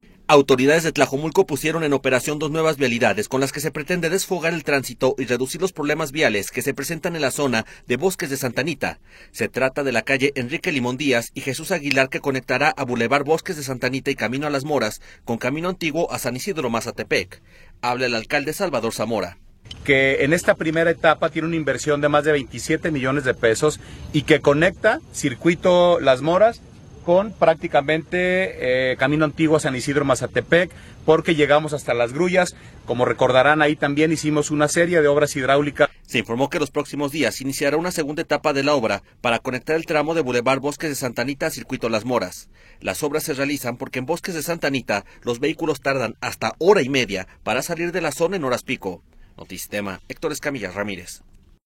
Habla el alcalde Salvador Zamora.